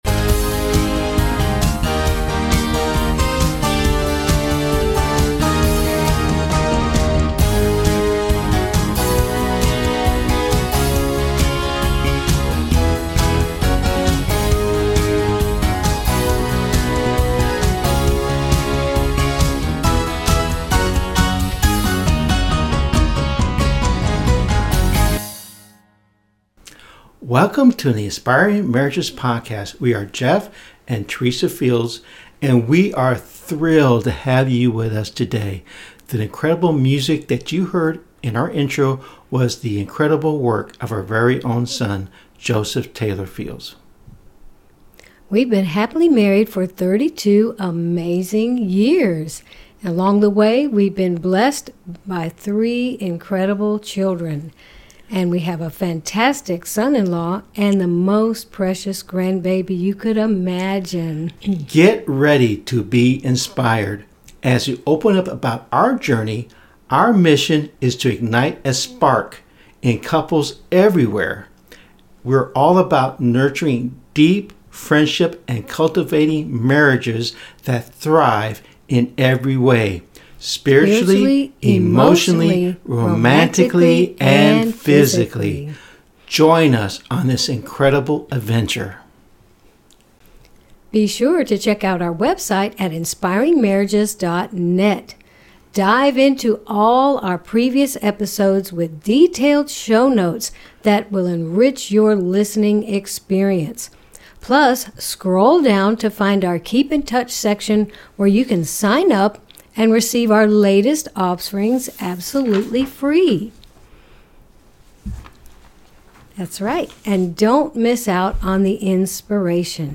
Their witty banter and relatable stories keep listeners engaged and laughing, while also emphasizing the importance of mutual respect and understanding in a thriving partnership. This episode is a delightful blend of humor and heartfelt insights, making it clear that love is not just a word; it’s the very fabric that holds our marriages together.